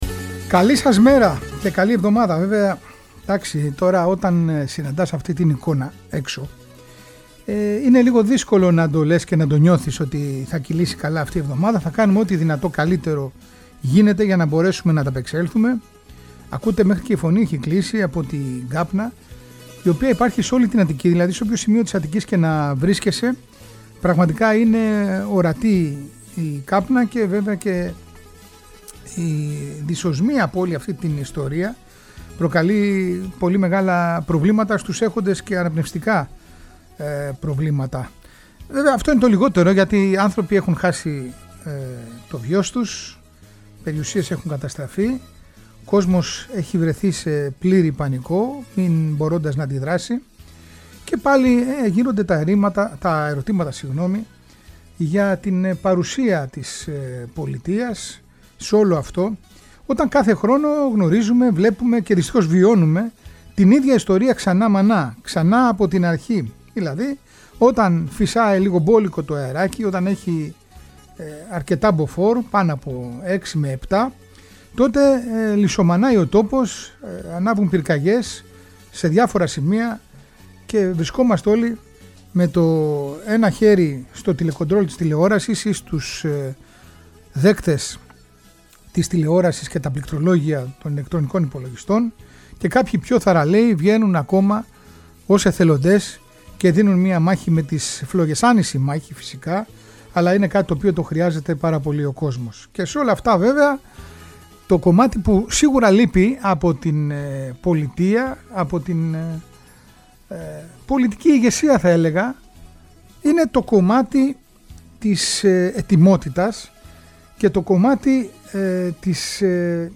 με μαρτυρίες ανθρώπων που έζησαν τους σεισμούς, δημοσιεύματα των εφημερίδων της εποχής, ακόμη και τραγούδια που γράφτηκαν για τη μεγάλη καταστροφή…Ένα αφιέρωμα μνήμης και σεβασμού στην ιστορία…